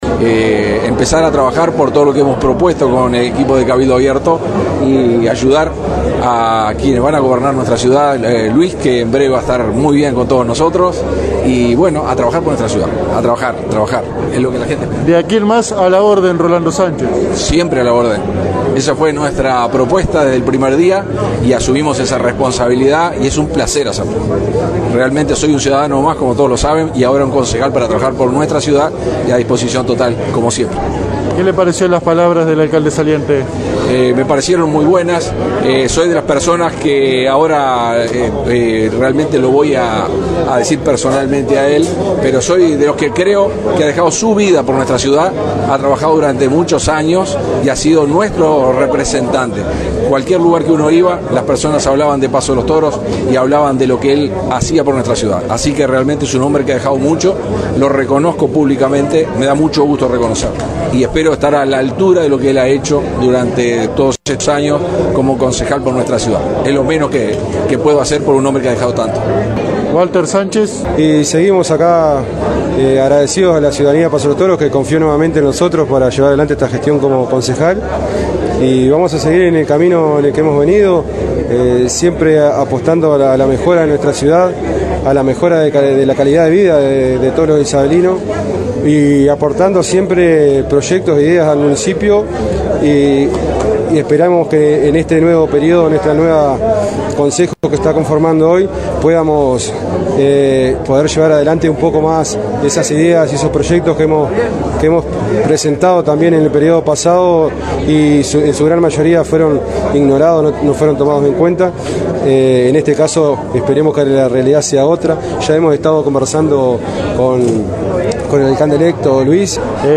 Cómo asumía el Concejo Municipal, entrevista de AM 1110 a cada uno de ellos